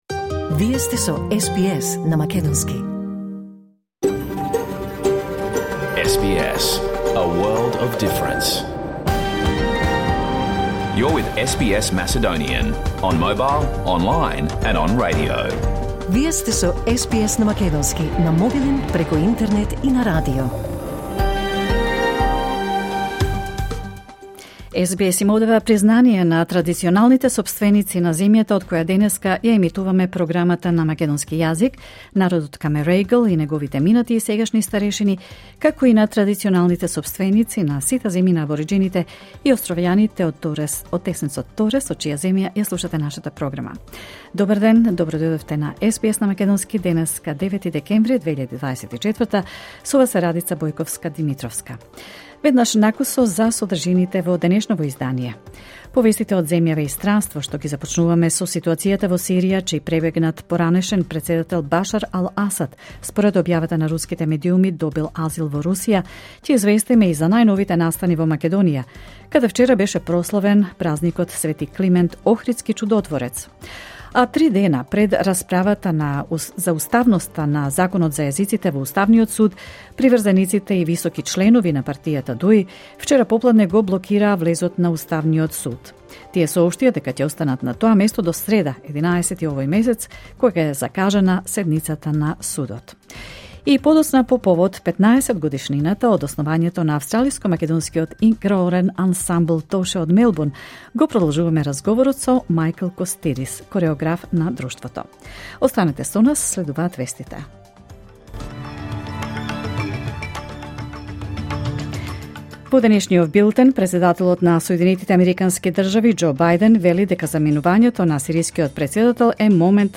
SBS Macedonian Program Live on Air 9 December 2024